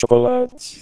perfilF0=[120*ones(1,N/3) 250*ones(1,N/3) 300*ones(1,N/3)]; // Atenção ao salto de F0: de 120 Hz para 250 Hz, depois para 300 Hz.